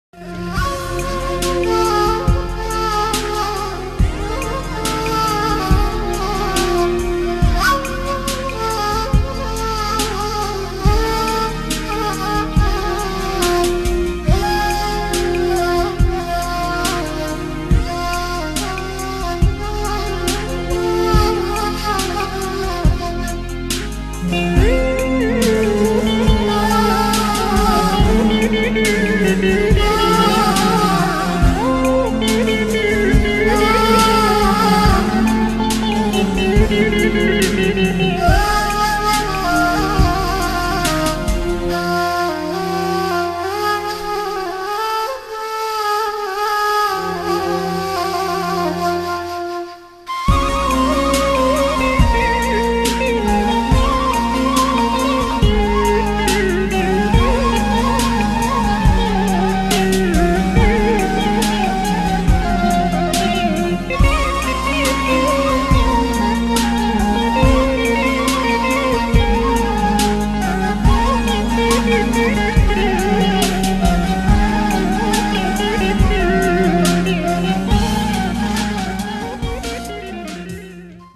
عزف الناي - تأسر القلوب في كل مكان ومنذ قديم الأزمان
نغمة-ناي-حزينه-جـدا-ابكتني-انا-شخصيامع-بعض-كلماتي.mp3